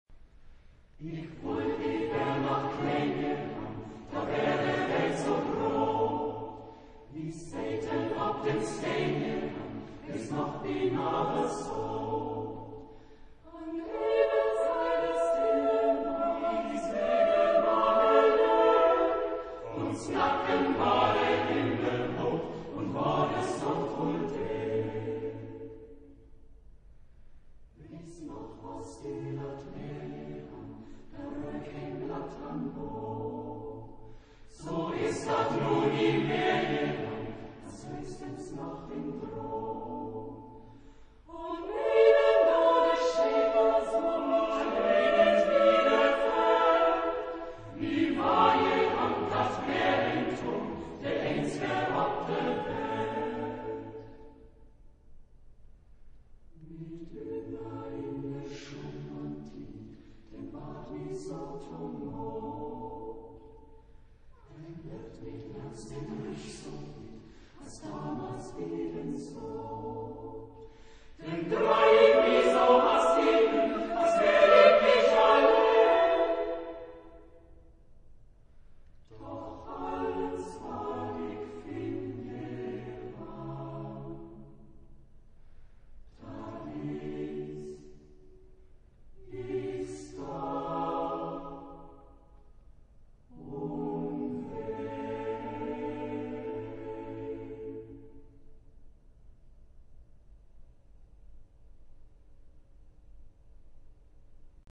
Genre-Stil-Form: Liedsatz ; weltlich
Chorgattung: SATB  (4 gemischter Chor Stimmen )
Solisten: Sopran (1)  (1 Solist(en))
Tonart(en): A-Dur
von Ensemble vocal Hamburg gesungen
Aufnahme Bestellnummer: 7. Deutscher Chorwettbewerb 2006 Kiel